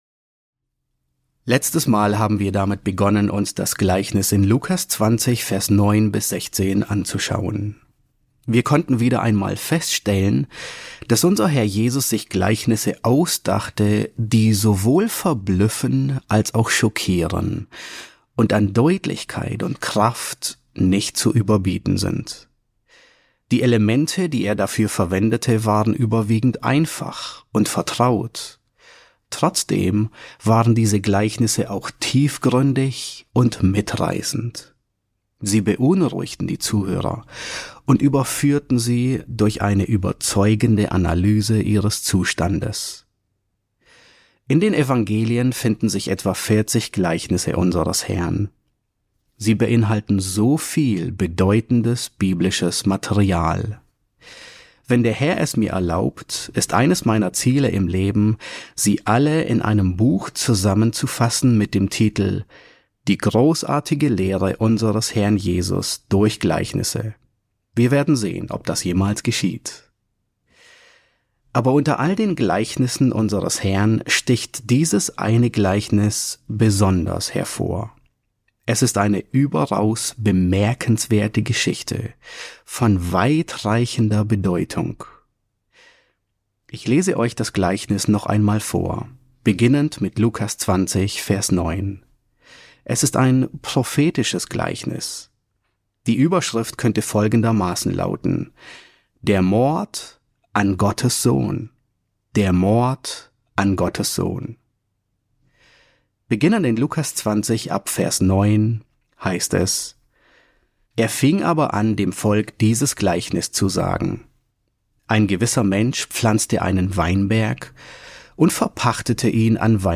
E4 S7 | Der Mord an Gottes Sohn: Ein prophetisches Gleichnis, Teil 2 ~ John MacArthur Predigten auf Deutsch Podcast